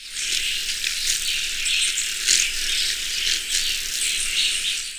Chlorostilbon maugaeus
VOZ: El canto es un chillido agudo. También emite otros chillidos al perseguir a otros colibríes.